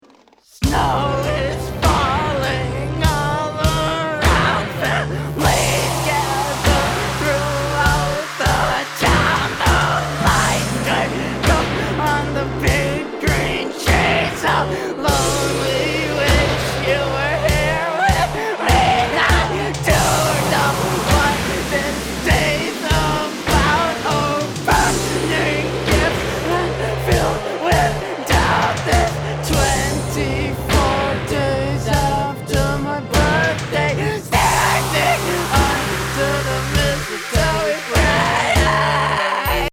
Boss DR5, keyboards